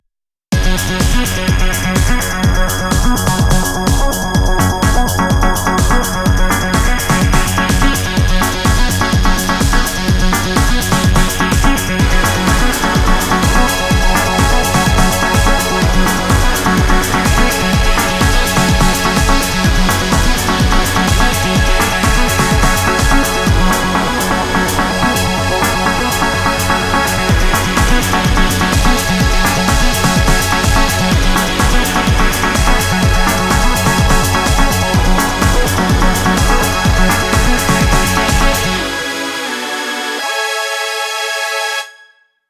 btw., Swarm does amazing strings.